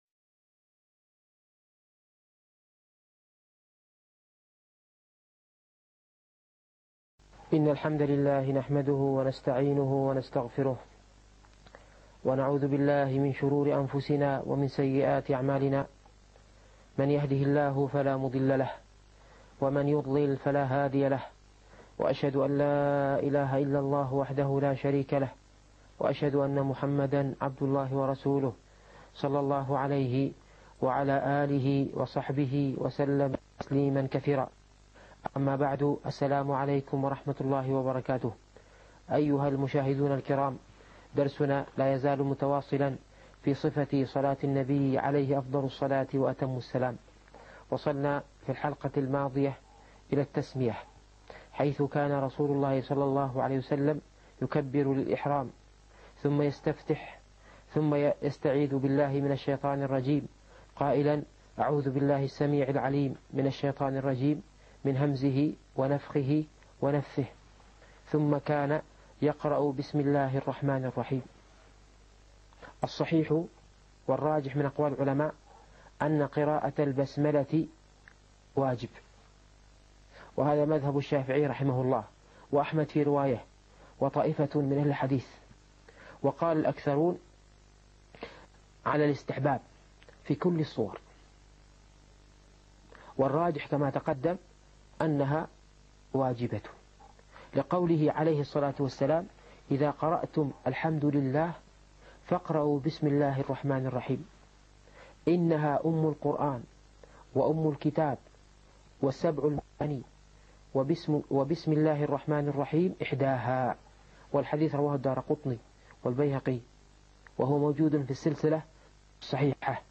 الفقه الميسر - الدرس الثامن عشر